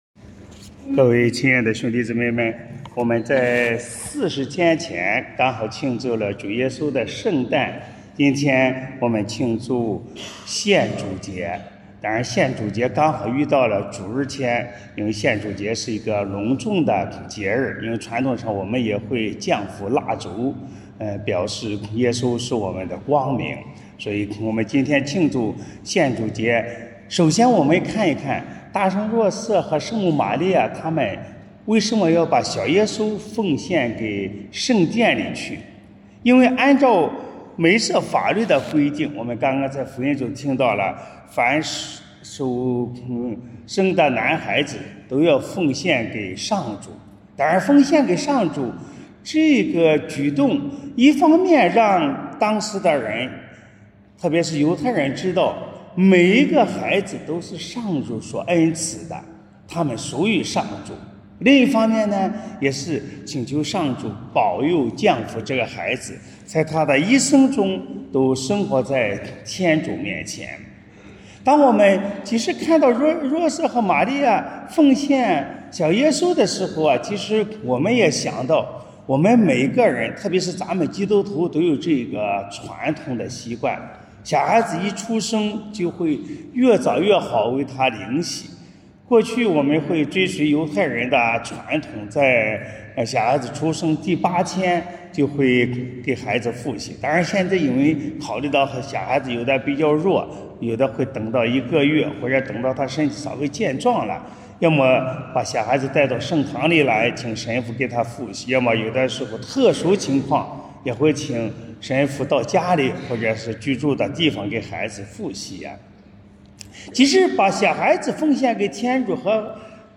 【主日证道】|奉献与接福（献主节）